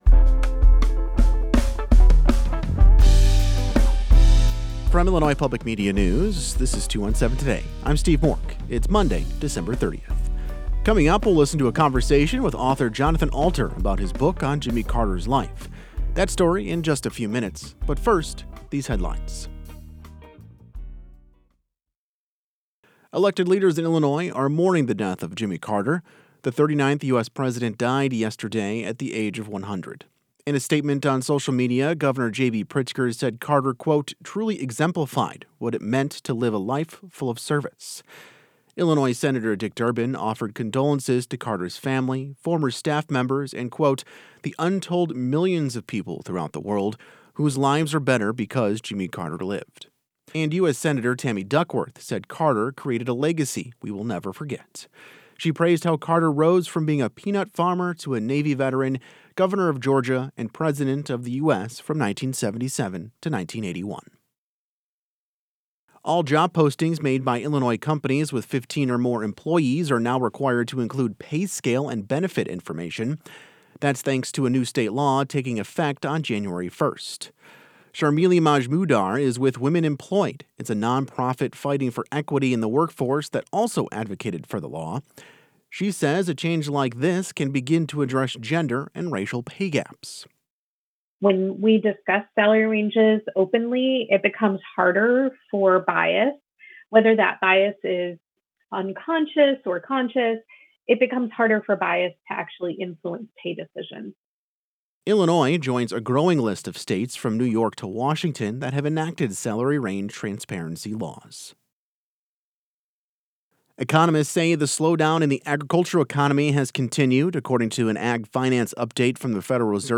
In today's deep dive, we'll listen to a conversation with author Jonathan Alter about his book on Jimmy Carter's life.